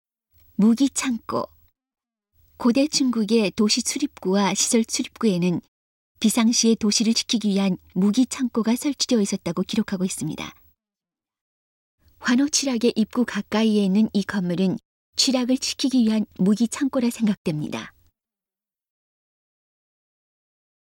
환호취락의 입구 가까이에 있는 이 창고를 환호를 지키기 위한 무기를 보관했던 무기고라고 상정했습니다. 음성 가이드 이전 페이지 다음 페이지 휴대전화 가이드 처음으로 (C)YOSHINOGARI HISTORICAL PARK